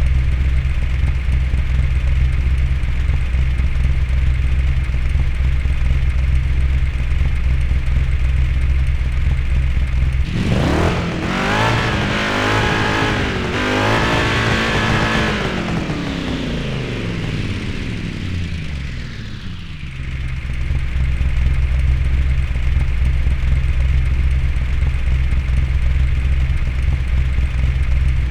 Index of /server/sound/vehicles/lwcars/buggy
idle.wav